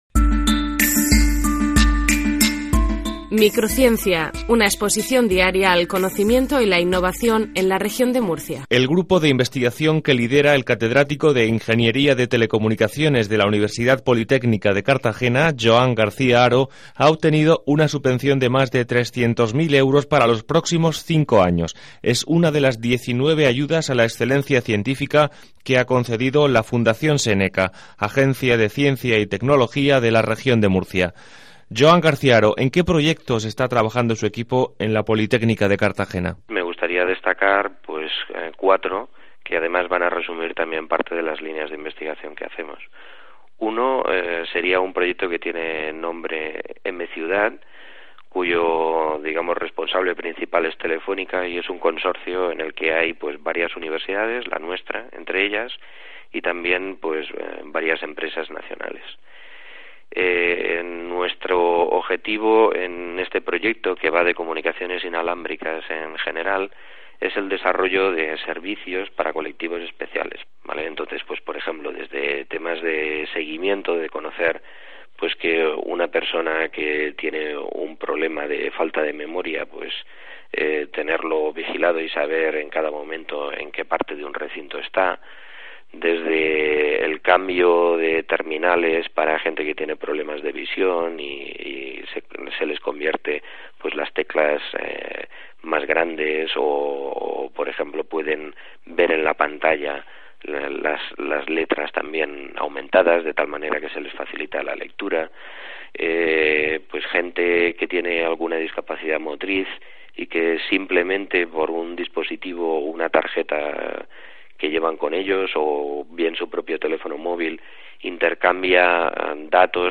Dentro del Programa de Comunicación Pública de la Ciencia y la Tecnología, la Fundación Séneca patrocina el programa de radio "Microciencia", emitido a través de Onda Regional de Murcia.
Entrevista